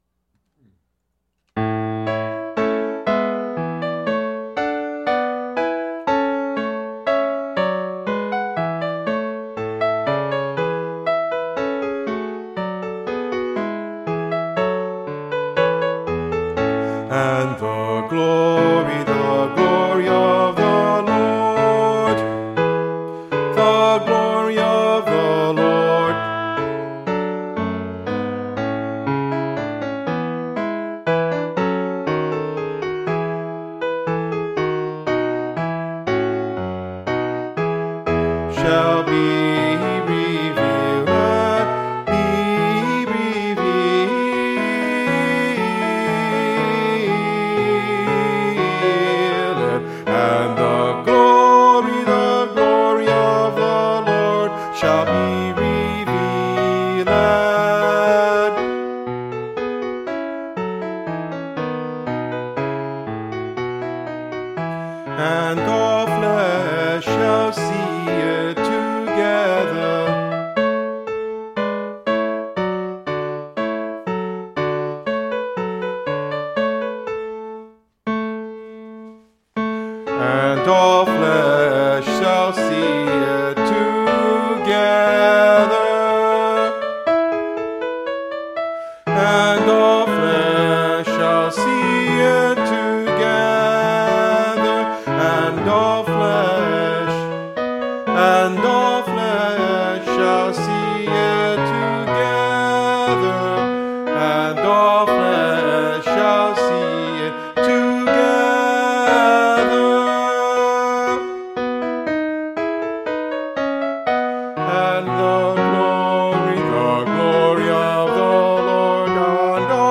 For Everybody else; Key of A (Original):
Alto